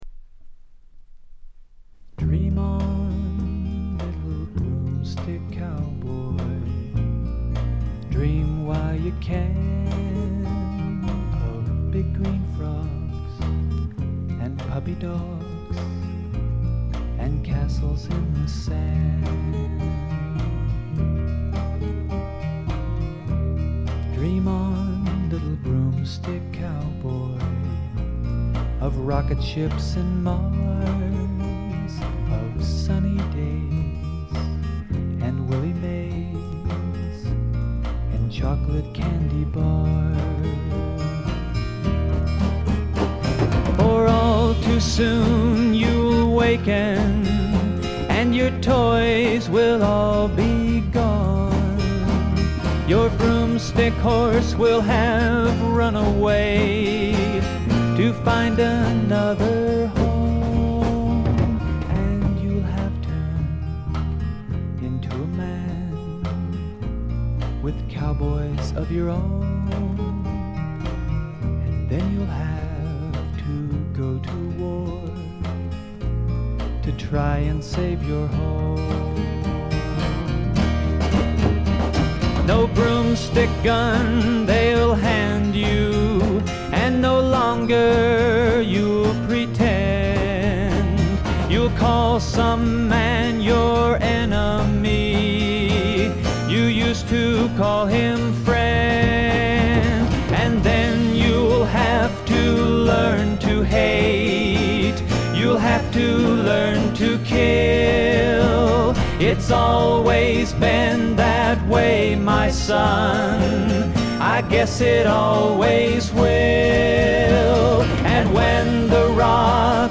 ごくわずかなノイズ感のみ。
マイナーなフォーキー・シンガーソングライター
試聴曲は現品からの取り込み音源です。
Guitar, Vocals Liner Notes